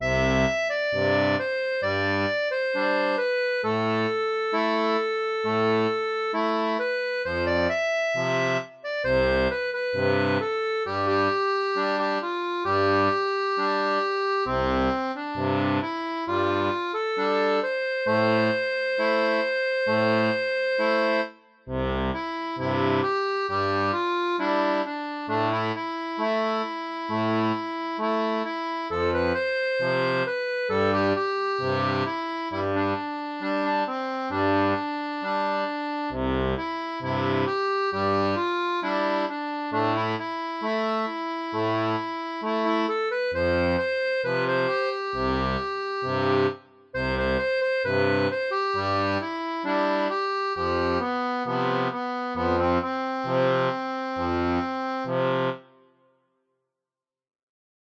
• un fichier audio (basé sur la tablature pour 2 rangs)
Chanson française